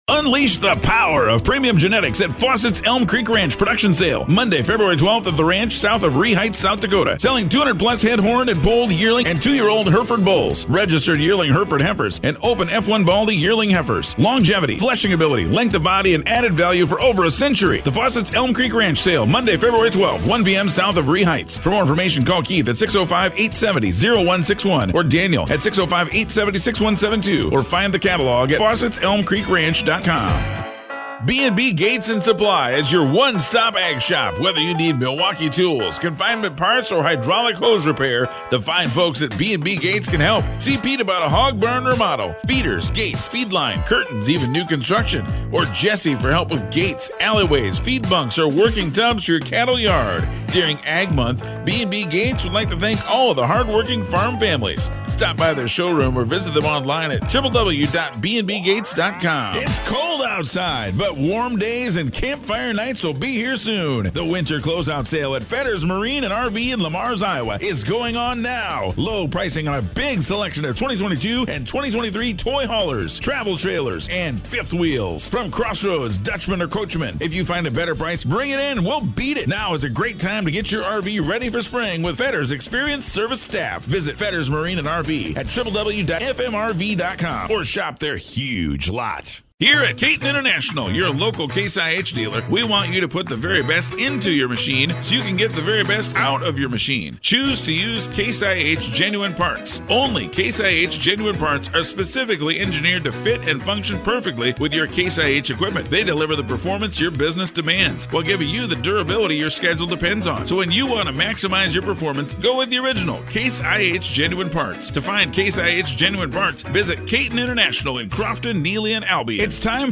Radio DJ - Audio Production - Voice Overs